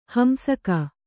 ♪ hamsaka